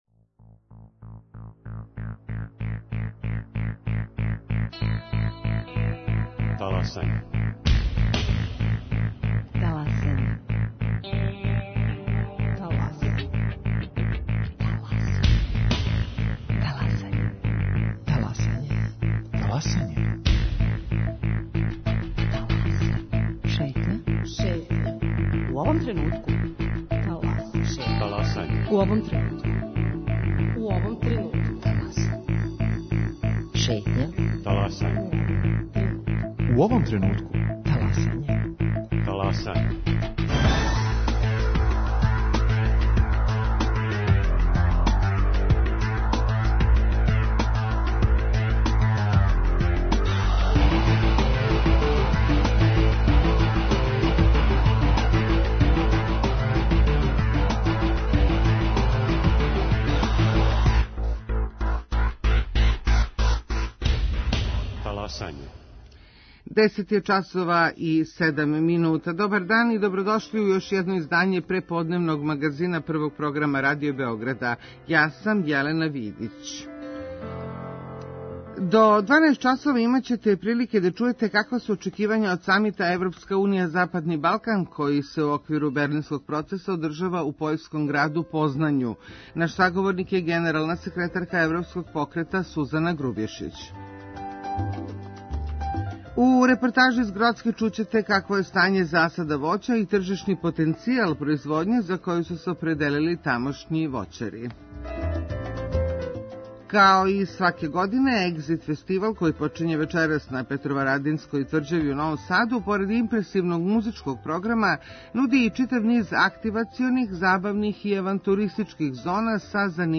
Са њима је разговарао о стању у засадима, али и о тржишном потенцијалу производње за коју су се определили.